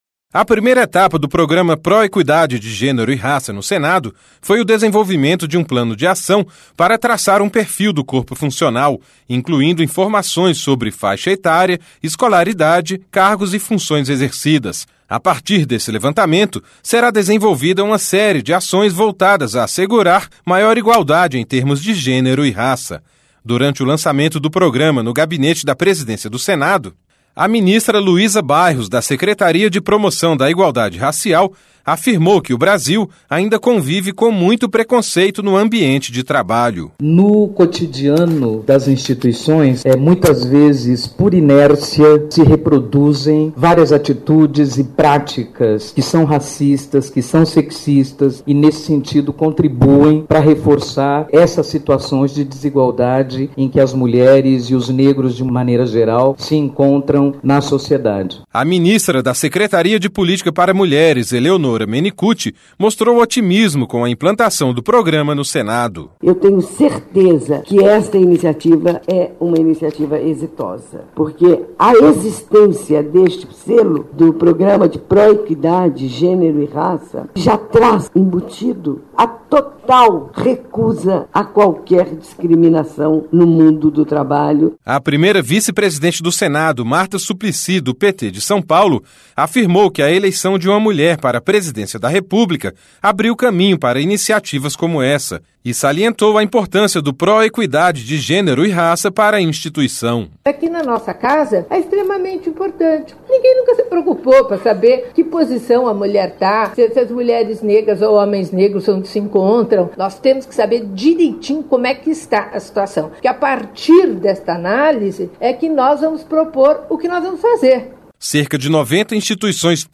(Repórter) A ministra da Secretaria de Políticas para Mulheres, Eleonora Menicucci, mostrou otimismo com a implantação do programa no Senado.